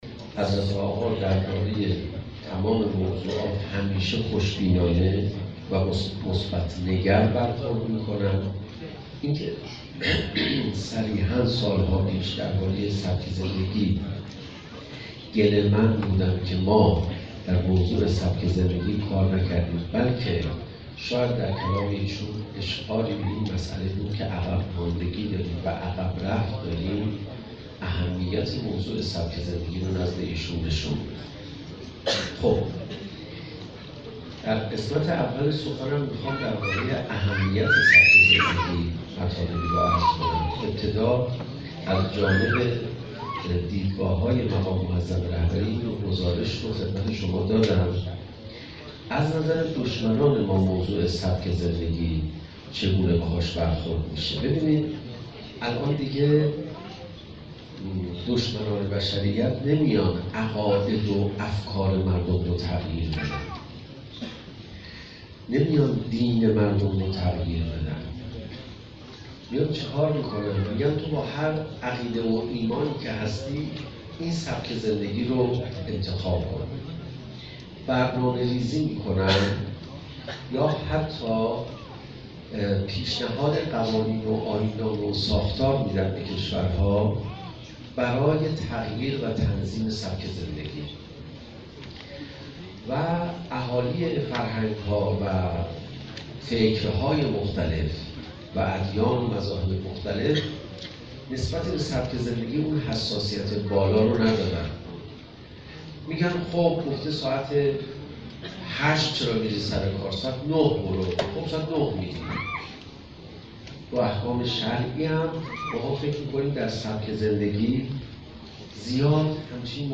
در چهاردهمین همایش کشوری ستاد فرهنگی فجر انقلاب اسلامی